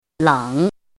“冷”读音
lěng
冷字注音：ㄌㄥˇ
国际音标：ləŋ˨˩˦